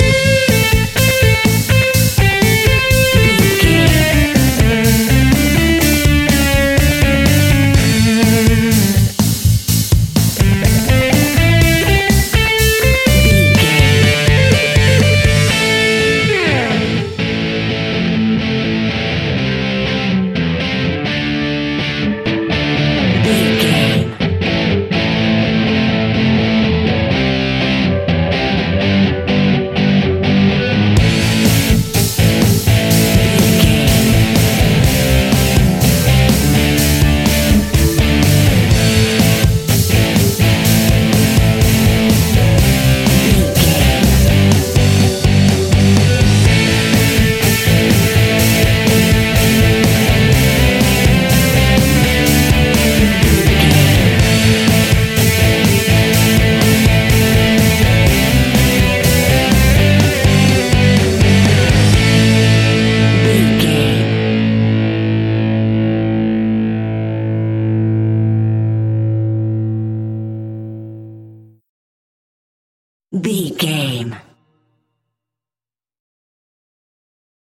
Epic / Action
Fast paced
Aeolian/Minor
hard rock
heavy metal
blues rock
distortion
rock guitars
Rock Bass
Rock Drums
heavy drums
distorted guitars
hammond organ